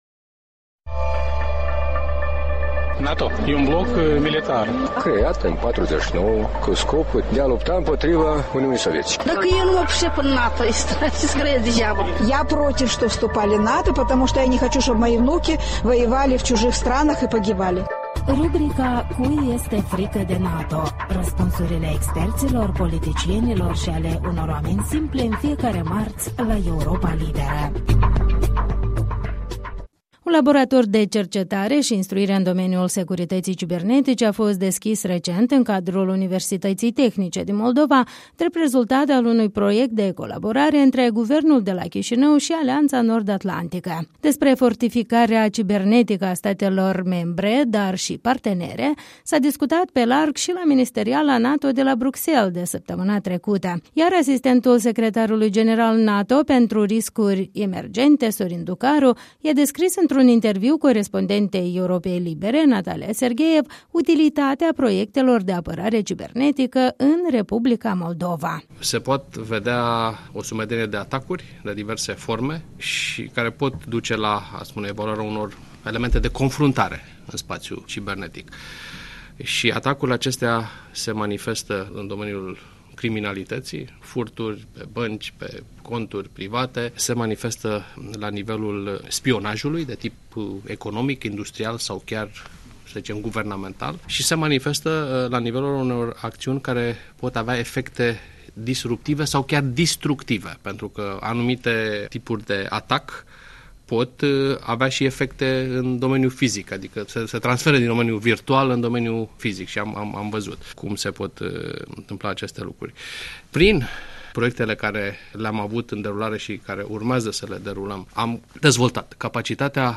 Interviu cu Sorin Ducaru